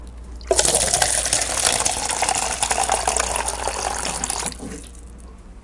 办公室气氛 " 办公室水冷门
描述：用Olympus DS40和Sony ECMDS70P录制的小办公室的声音。饮水机出水的单音录音。
Tag: 冷却器 现场记录 办公室